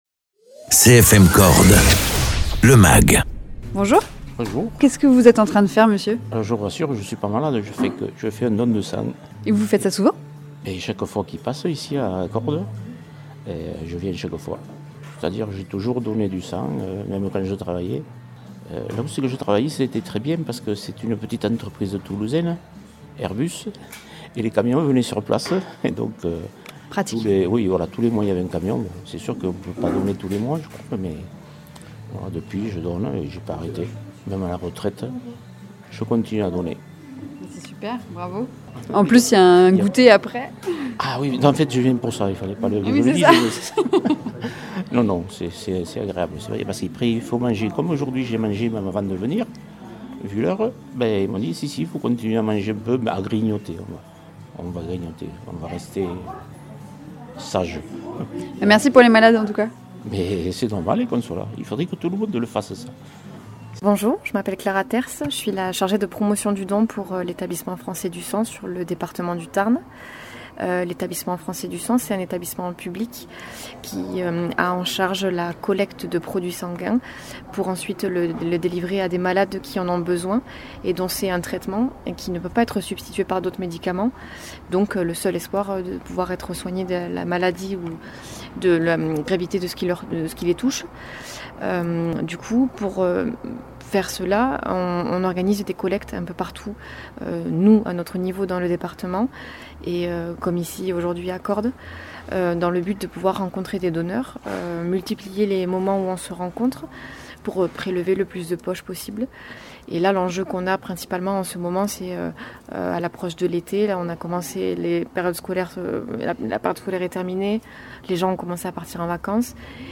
A la faveur d’une collecte organisée à Cordes-sur-ciel, nous sommes allés à leur rencontre afin de souligner l’importance des dons et la procédure.
Interviews